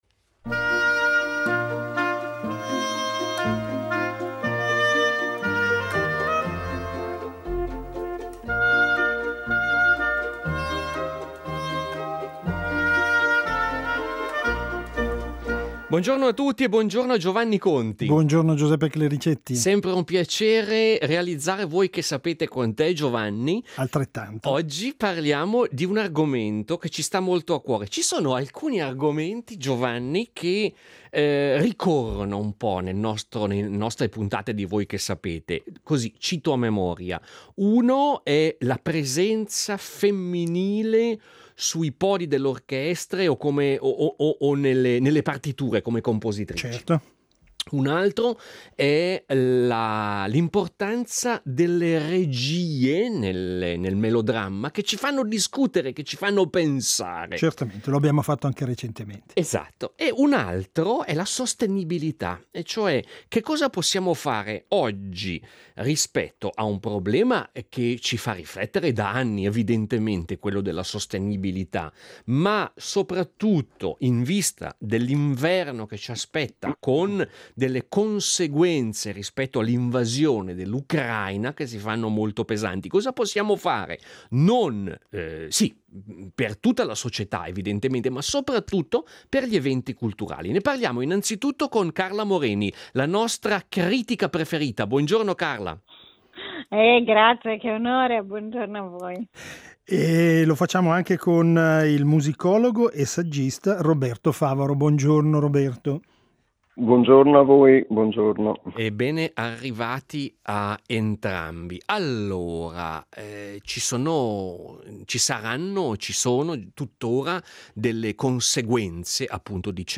ne discutono con la critica musicale